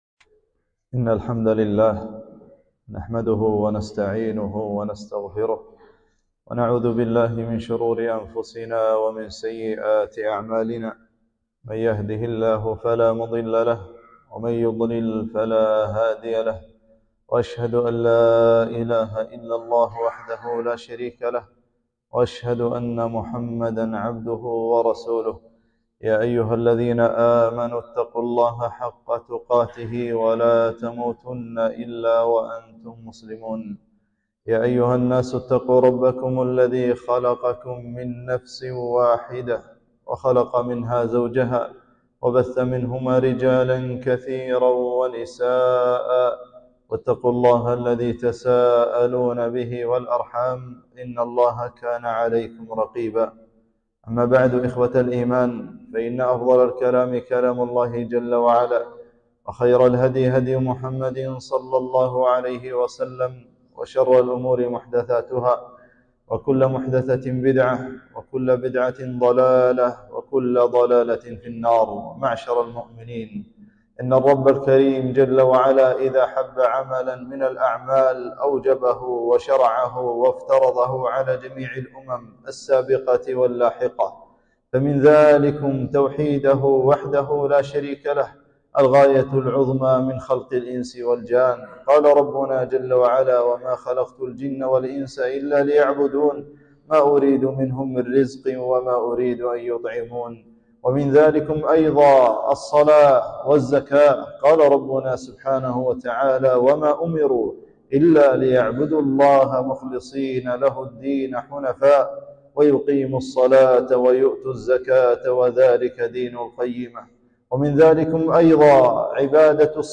خطبة - أعمال في رمضان